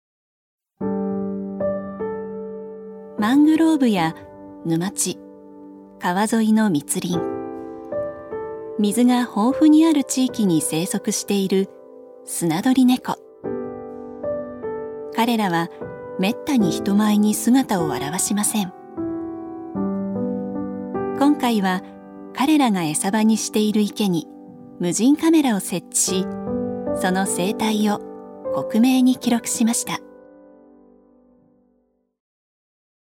女性タレント
ナレーション６